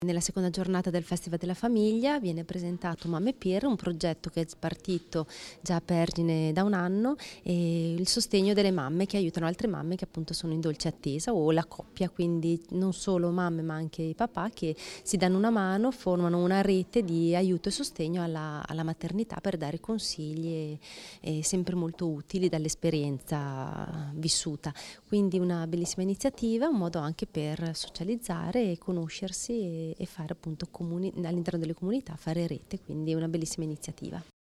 Presentate al Festival della Famiglia le esperienze trentine di sostegno fra pari nel momento della nascita di un figlio
Segnana_Convegno_Mamme_Peer.mp3